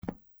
较硬的地上脚步声－右声道－YS070525.mp3
通用动作/01人物/01移动状态/01硬地面/较硬的地上脚步声－右声道－YS070525.mp3